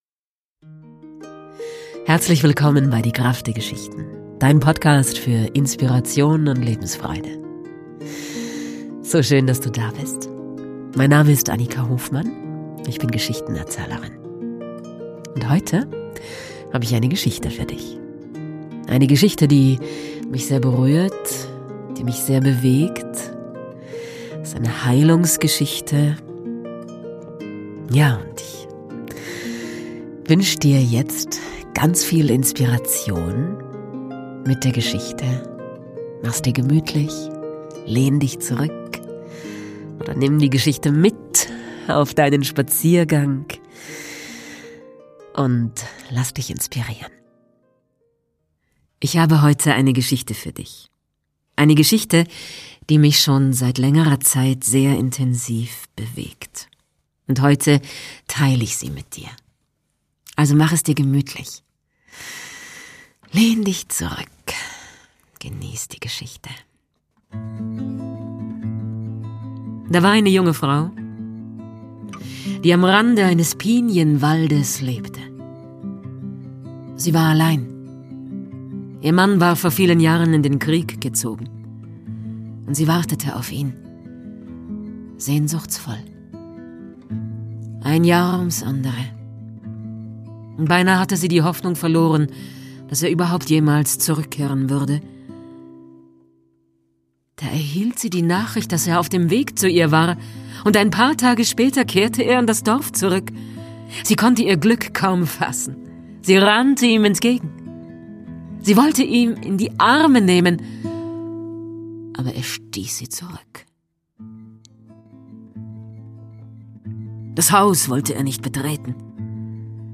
Es ist eine Heilungsgeschichte. Sie ist aus der Wolfsfrau von Clarissa Pinkola Estés. Ich spiele Gitarre dazu, während ich erzähle.